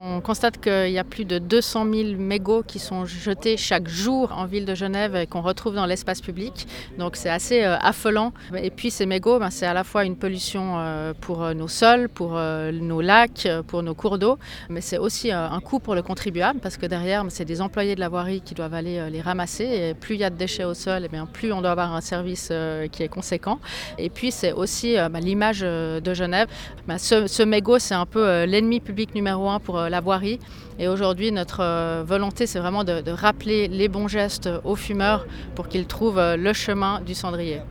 Les explications de Marie Barbey-Chapuis, conseillère administrative en charge de la voirie.